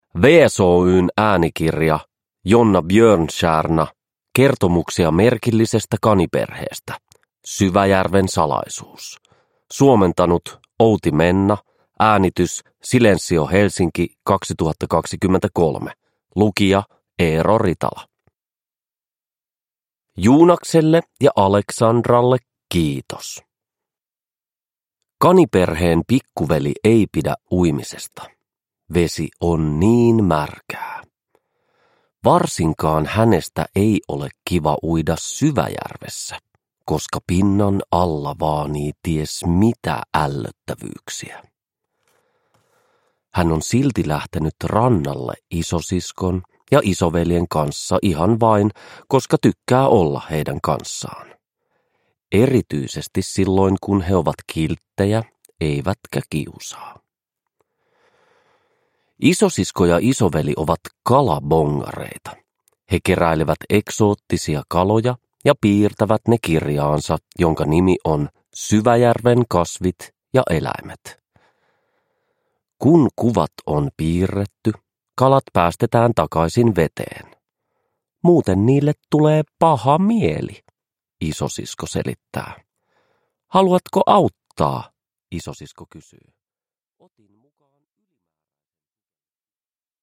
Kertomuksia merkillisestä kaniperheestä: Syväjärven salaisuus – Ljudbok – Laddas ner